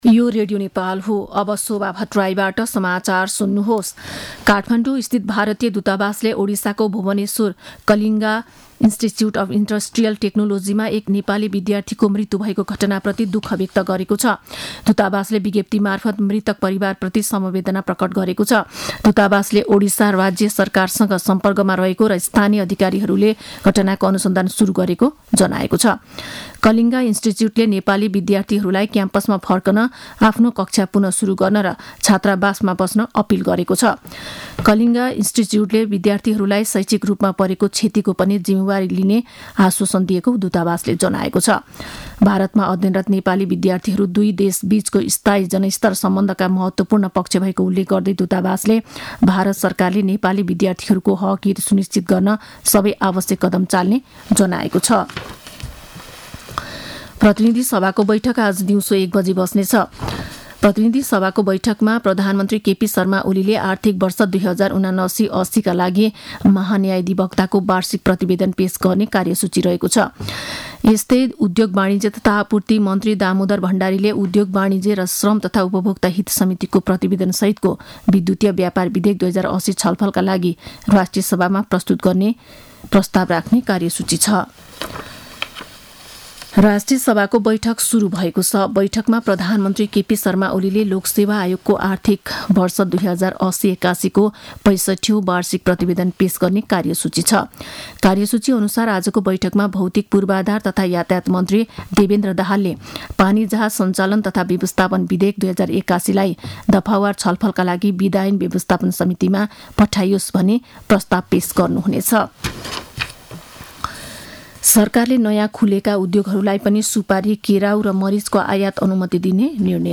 मध्यान्ह १२ बजेको नेपाली समाचार : ७ फागुन , २०८१